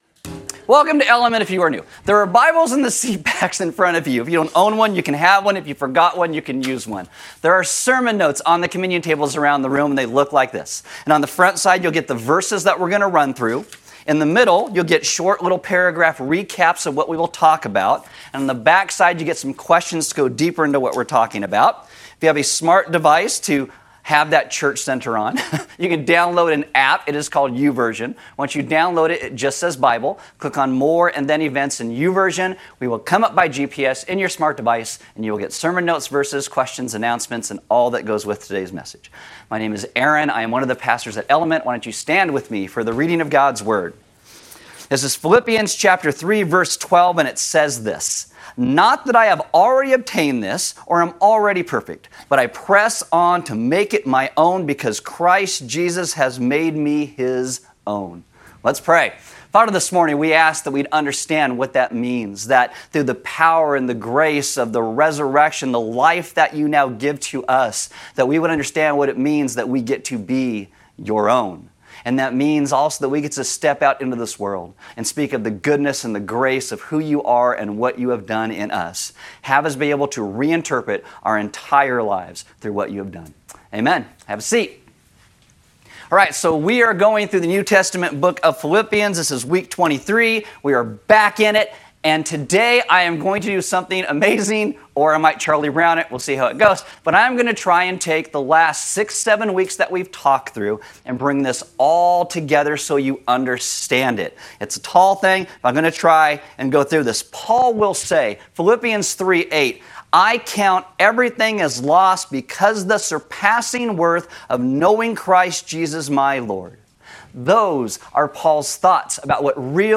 A message from the series "Didn't See That Coming." This week, we looked at what Israel lost in their exile and how this affected their hope and expectations of the Messiah.